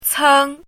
chinese-voice - 汉字语音库
ceng1.mp3